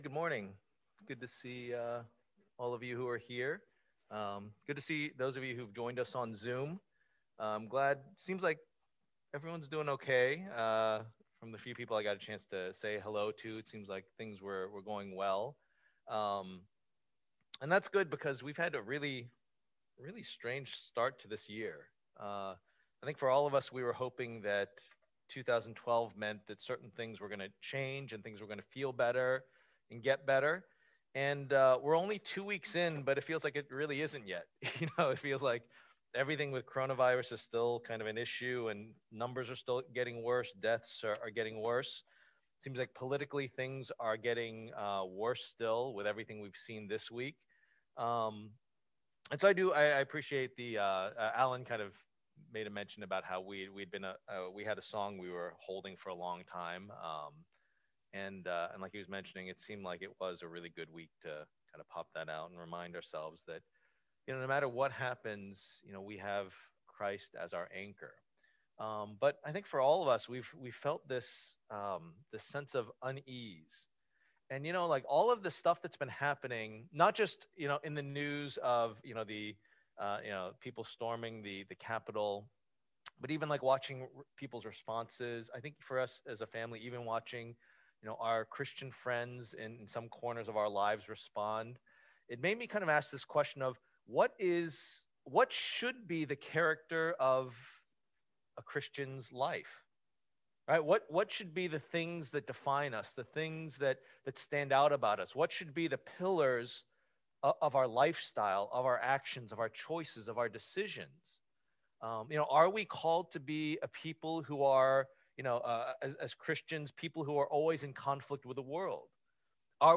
Preacher
Service Type: Lord's Day